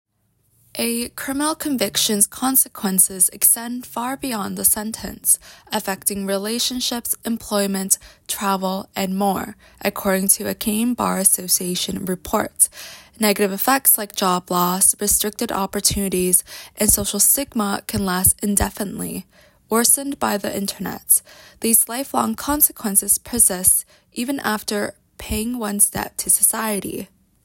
Audio summary courtesy of volunteer